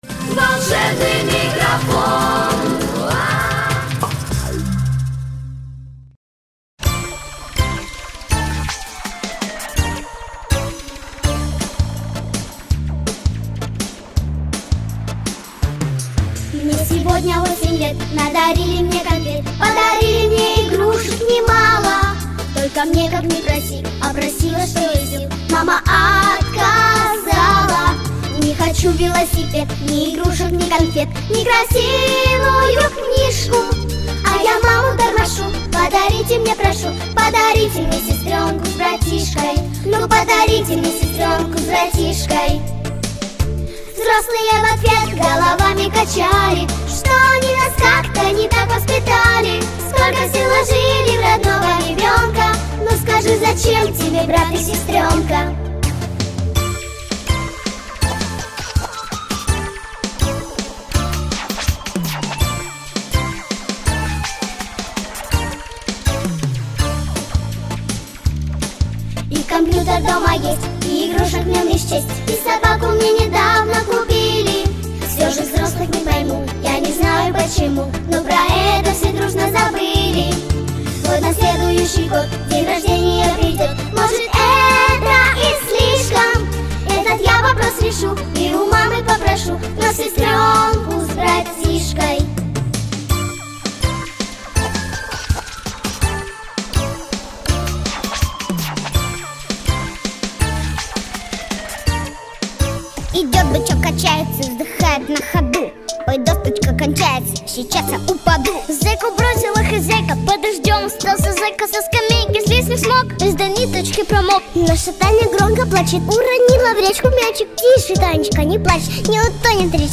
Песня со словами (плюс)